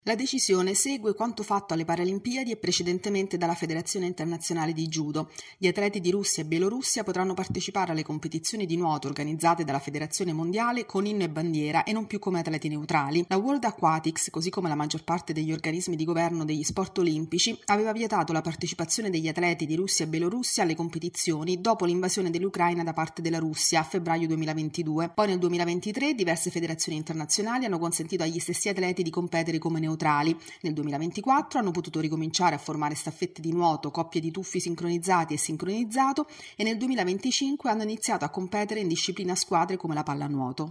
Sport